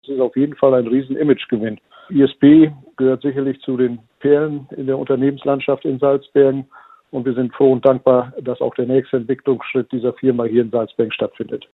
Andreas Kaiser, Bürgermeister von Salzbergen